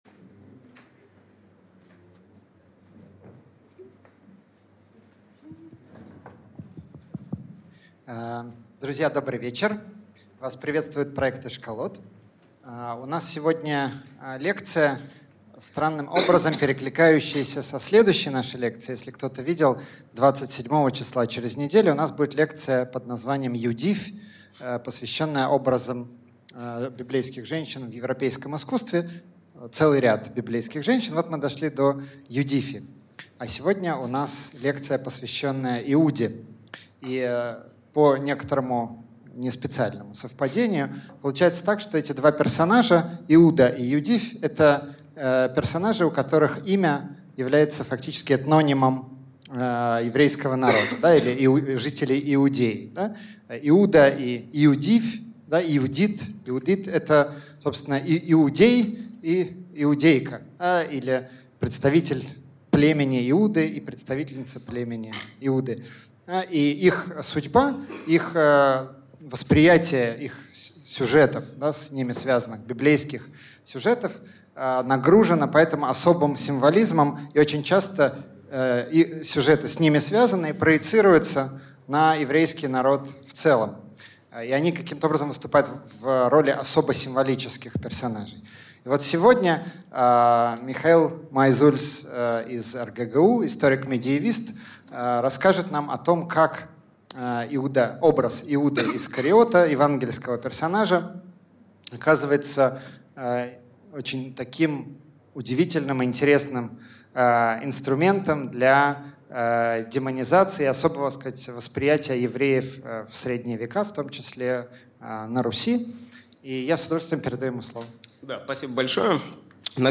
Аудиокнига Иуда Искариот в европейской и еврейской культуре | Библиотека аудиокниг